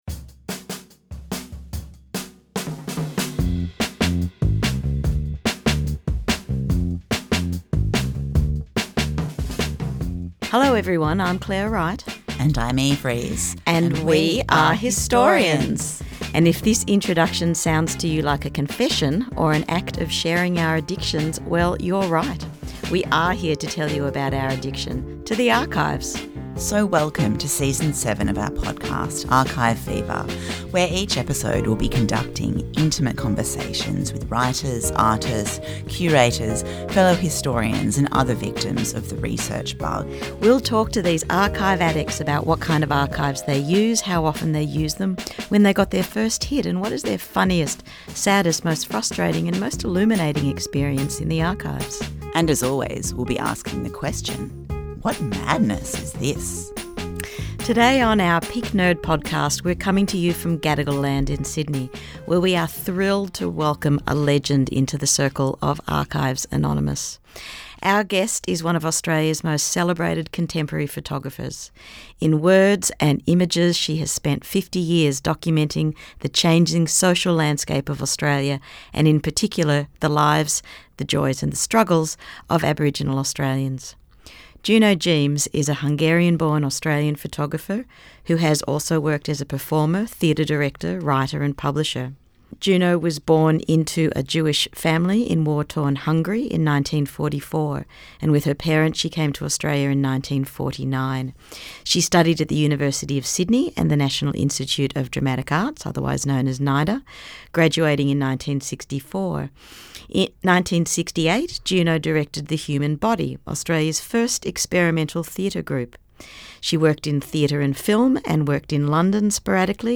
Archive Fever is a new Australian history podcast featuring intimate conversations with writers, artists, curators, fellow historians and other victims of the research bug.